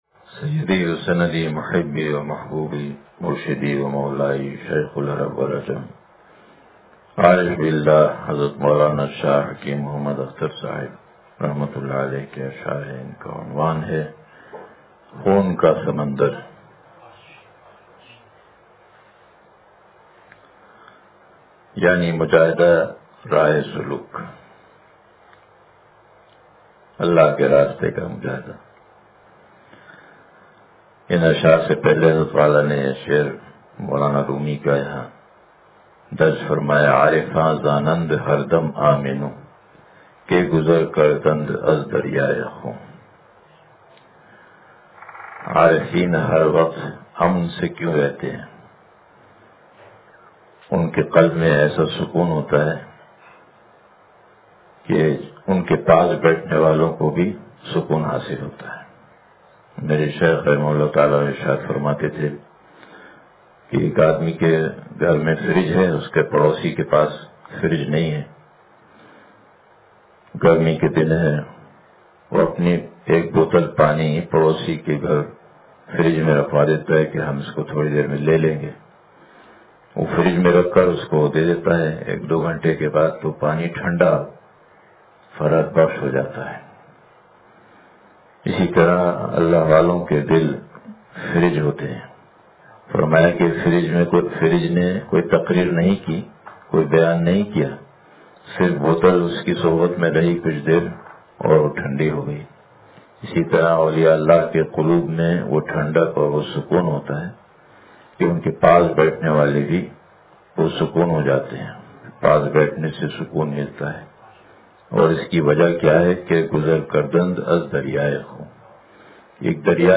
خون کا سمندر – مجلس بروز اتوار